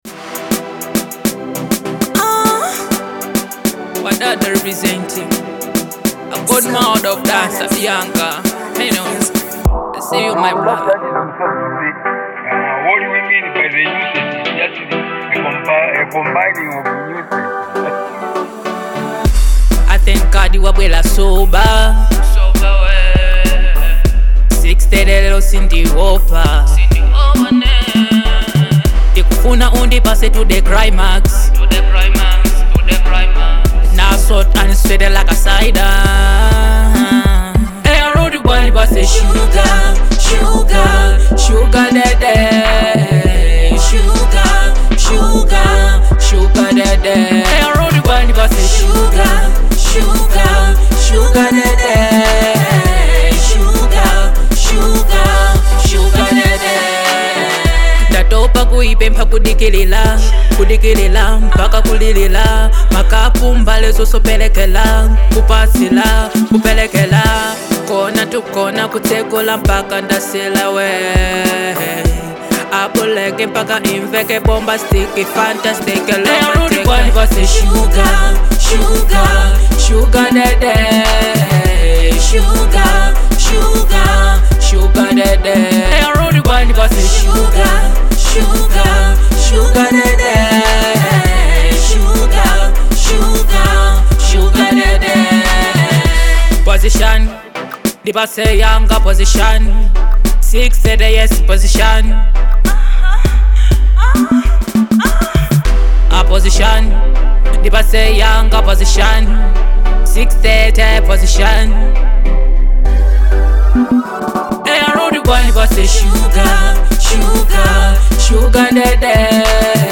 Genre: Dancehall